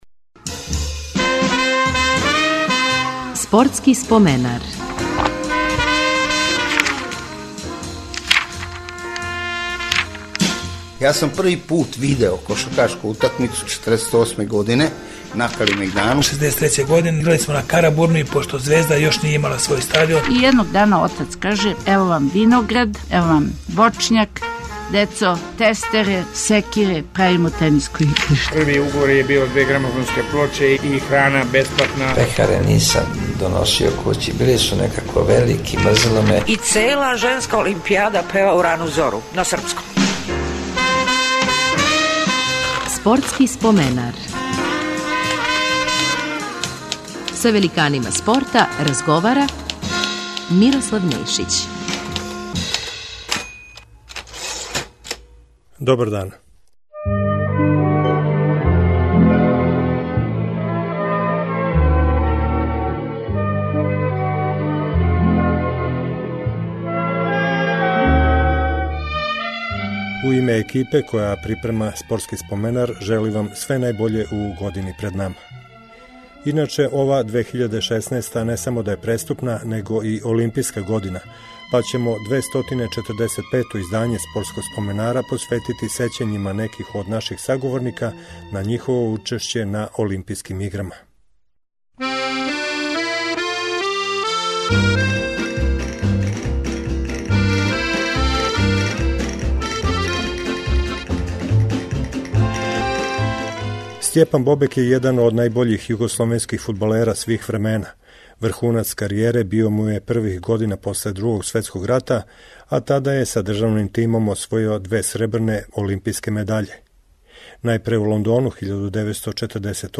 Прва емисија у 2016, олимпијској години, биће посвећена учешћу неких од гостију Спортског споменара на Олимпијским играма. Уз коришћење архивских тонских записа са такмичења, емитоваћемо сећања фудбалера Стјепана Бобека о чувеним мечевима Југославије и СССР-а у Хелсинкију 1952. маратонца Фрање Михалића на освајање сребрне медаље у Мелбурну четири године касније.